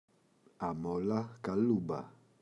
αμόλα καλούμπα [a’mola ka’lumba]
αμόλα-καλούμπα.mp3